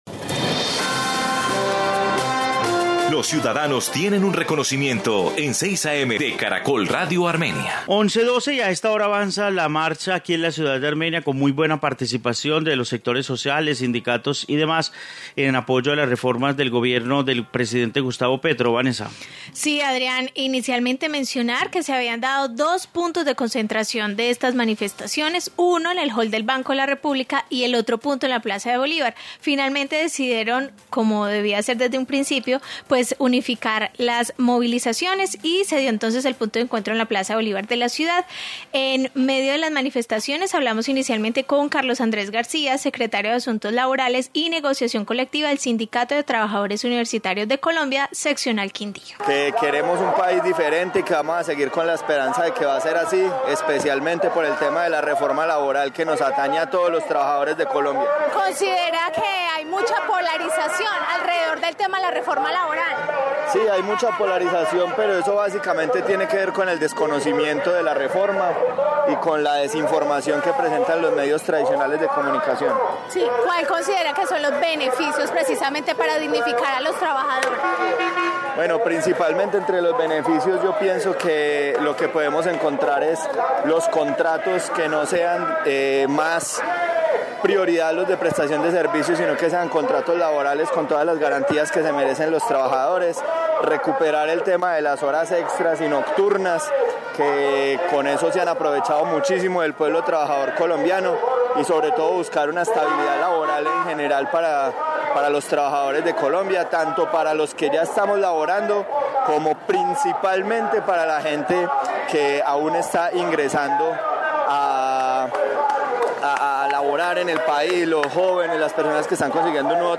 Informe marcha en Armenia